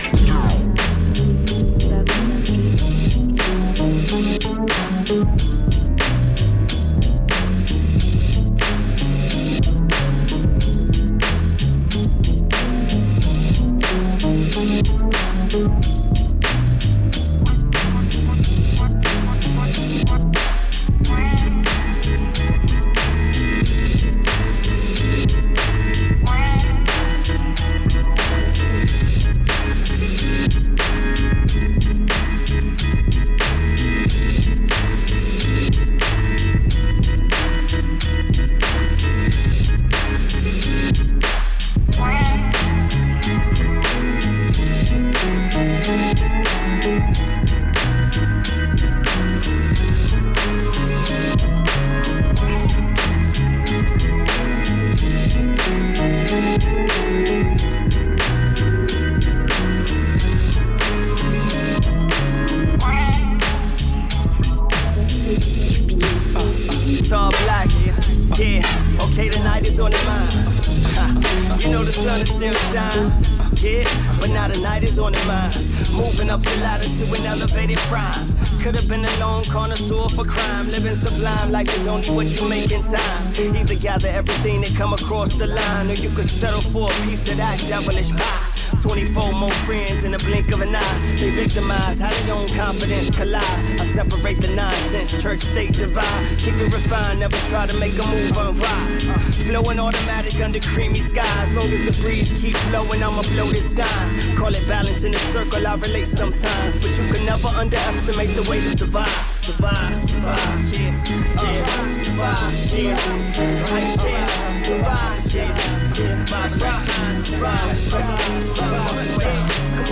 Chicago underground MC
Official SXSW 2011 mix.